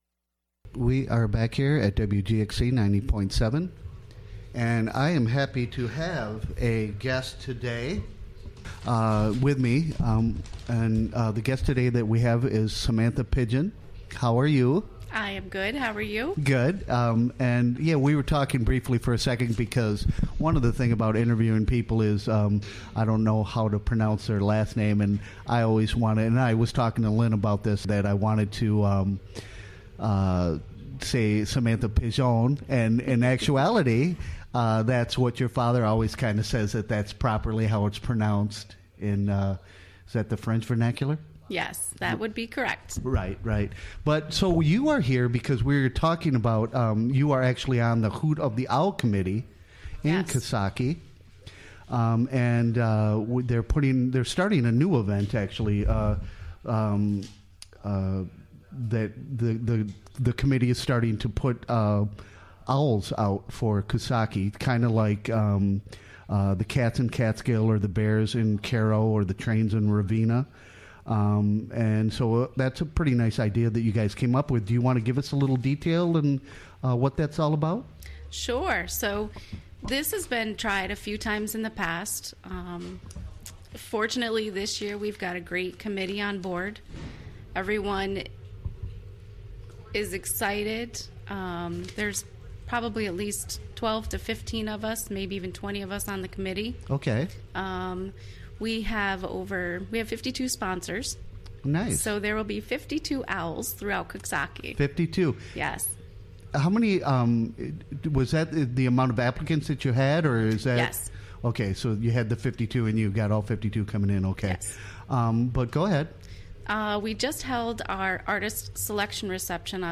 Recorded during the WGXC Morning Show.